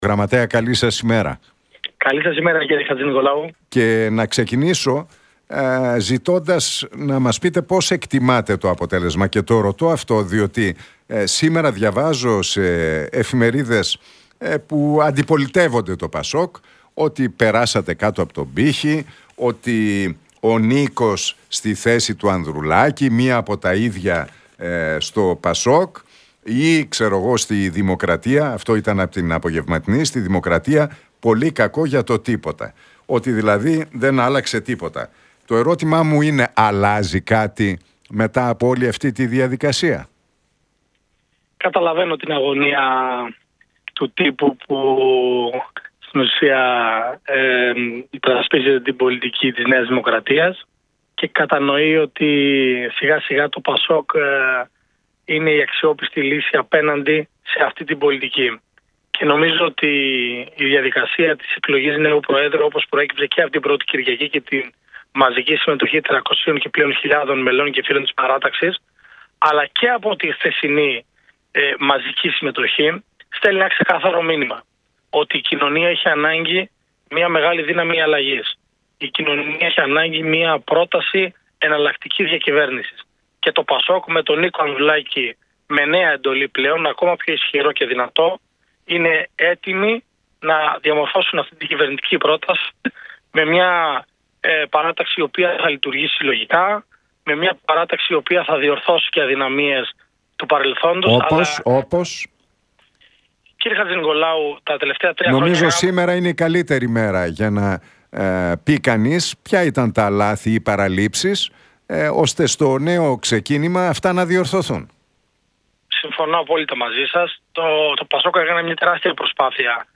Για το εκλογικό αποτέλεσμα και την επόμενη ημέρα στο ΠΑΣΟΚ μίλησε ο Γραμματέας του κόμματος, Ανδρέας Σπυρόπουλος στον Realfm 97,8 και τον Νίκο Χατζηνικολάου.